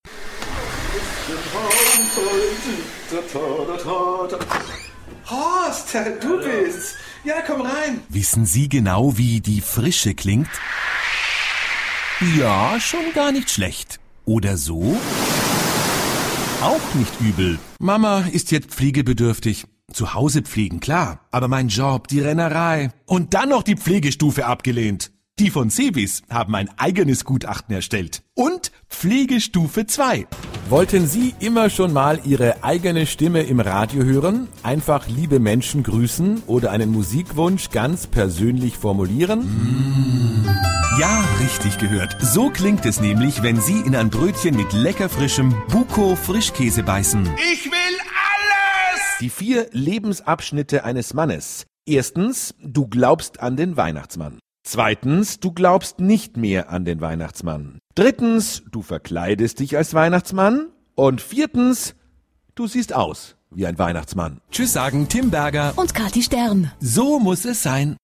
Almanca Seslendirme
Erkek Ses